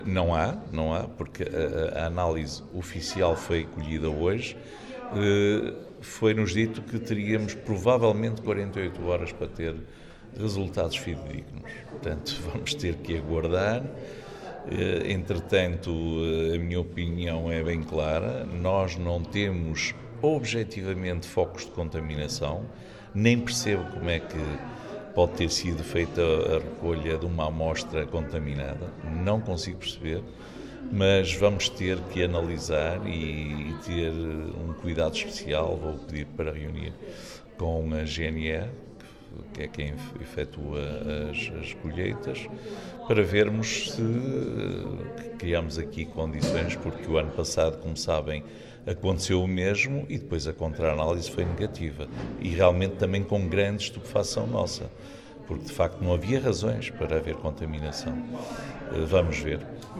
Foi esta a explicação de Benjamim Rodrigues, presidente da câmara de Macedo de Cavaleiros, adiantou no início desta tarde: